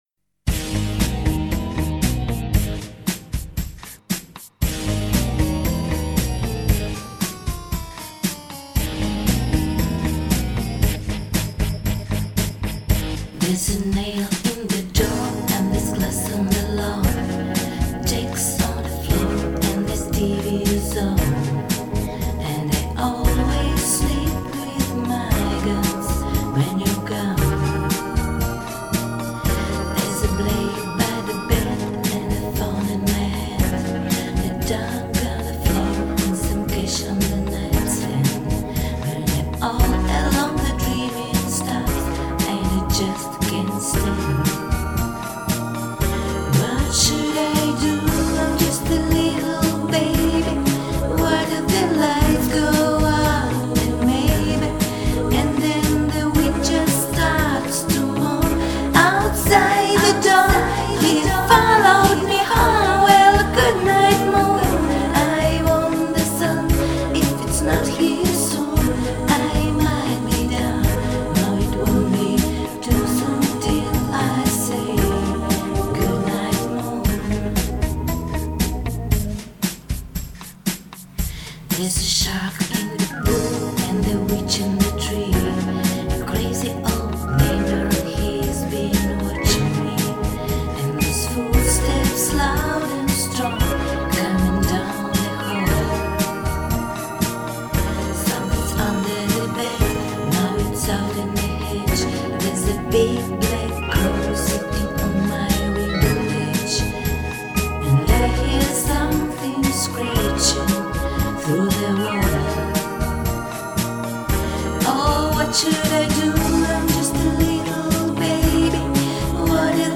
Пела охрипшая, без голоса, с температурой, давненько уже.